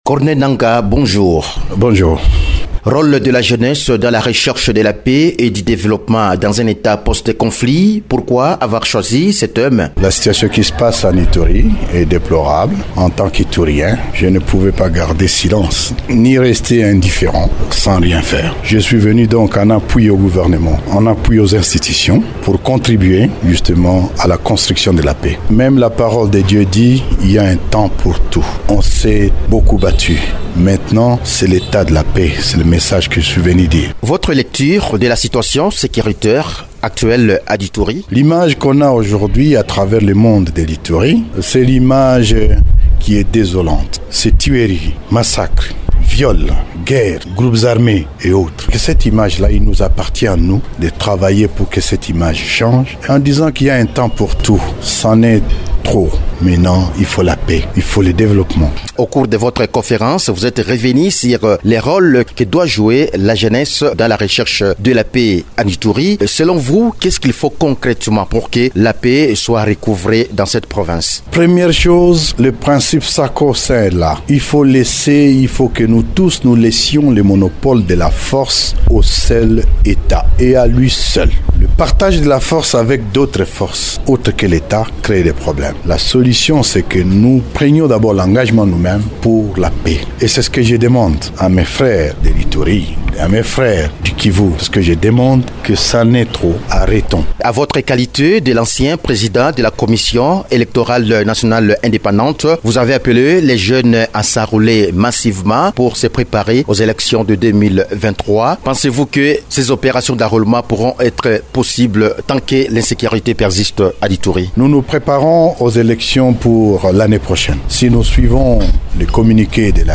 Il a lancé son invitation dans une interview exclusive accordée à Radio Okapi, après son séjour de 48 heures à Bunia, chef-lieu de l’Ituri.